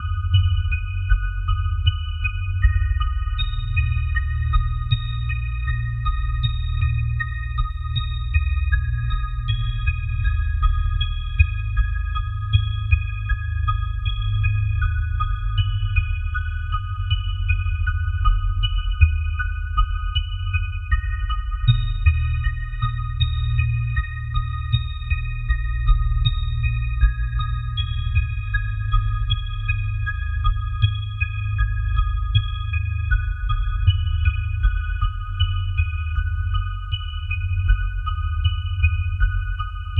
I am loving the sound of this synth, it sounds soo full whatever type of sound you conjure up. No effects mind you except some delay :wink: